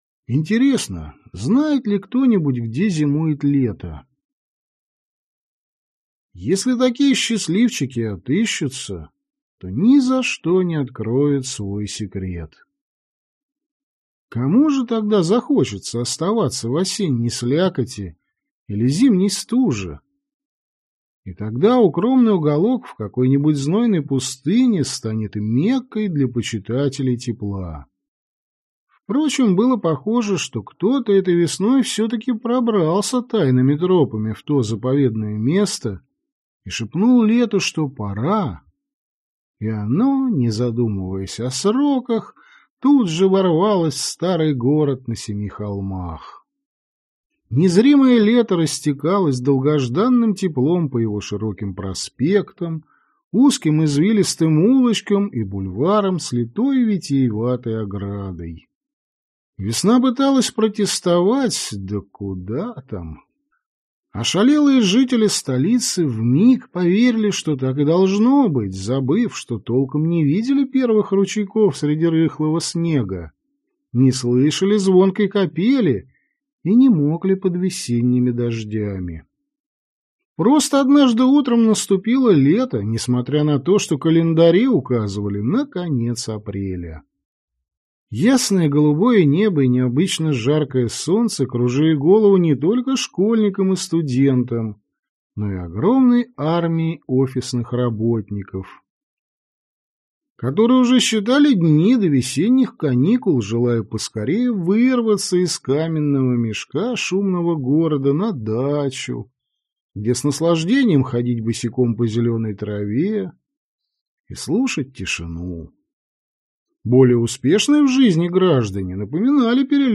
Аудиокнига Царское имя | Библиотека аудиокниг